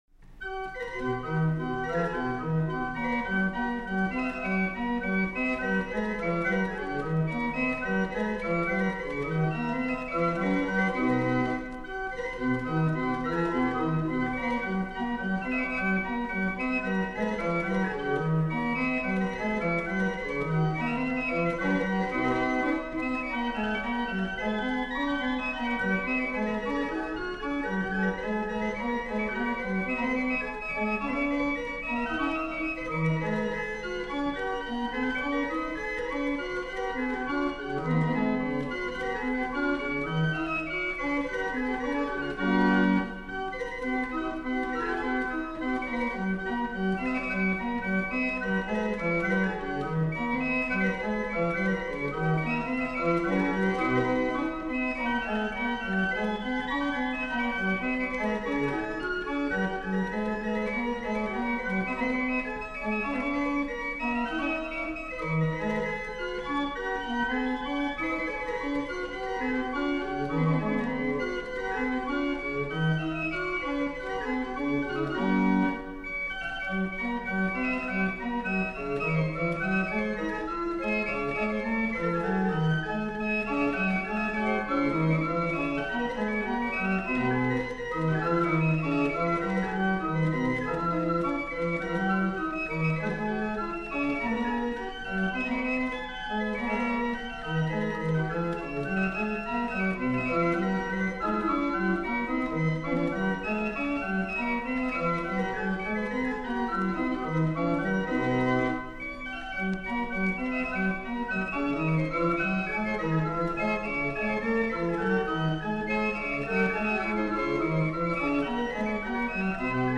Also, I've been looking at a performance of Haendel, as preserved in a historic barrel organ: